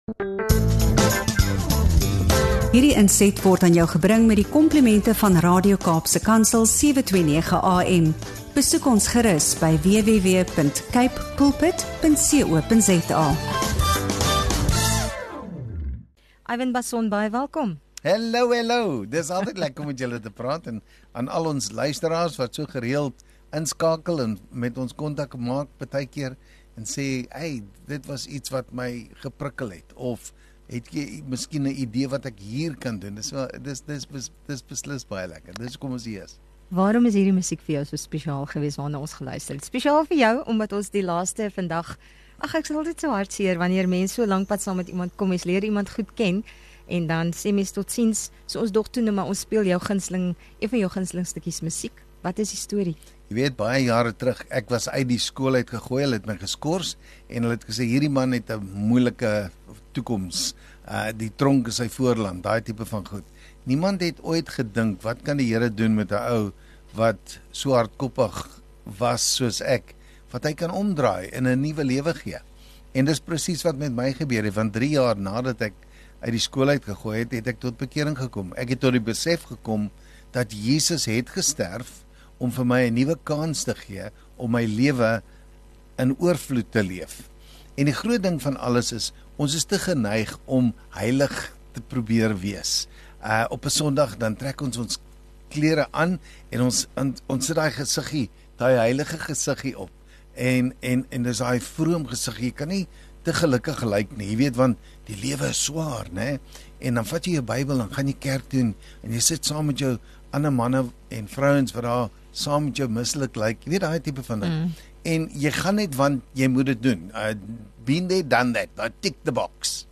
In hierdie inspirerende onderhoud op Radio Kaapse Kansel